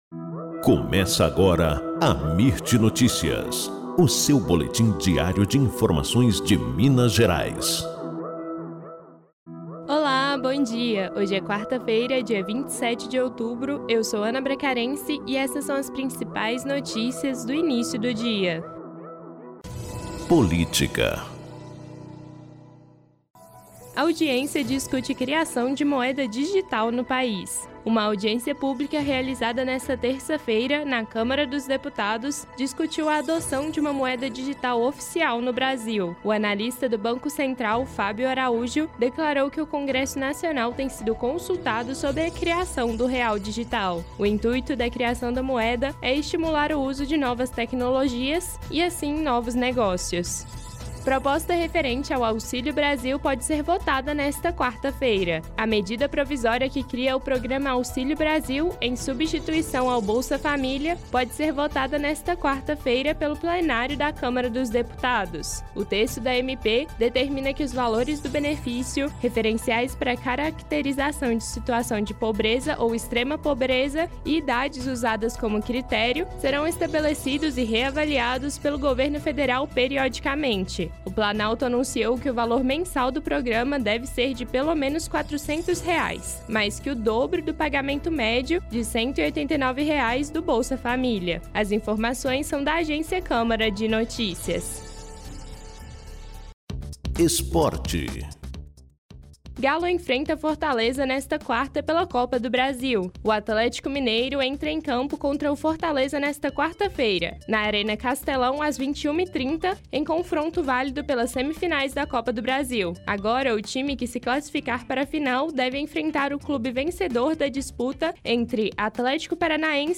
Boletim Amirt Notícias – 27 de outubro